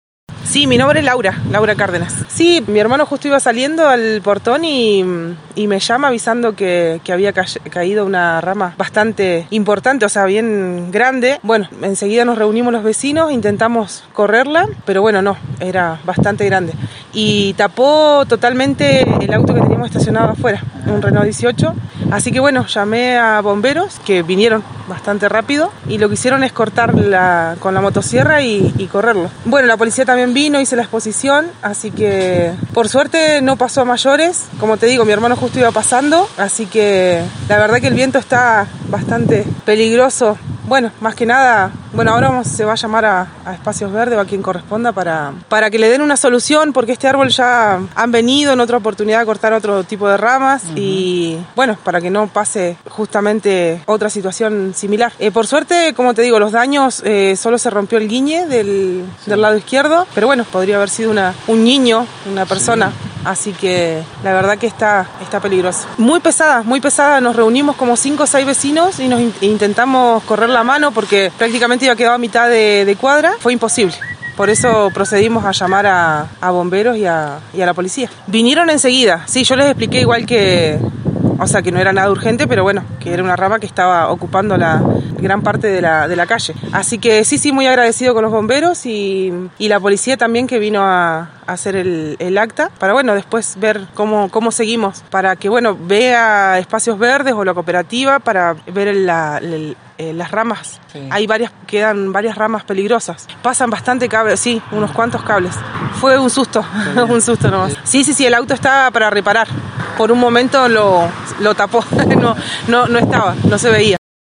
Noticias de Esquel estuvo en el lugar y conversó con la damnificada.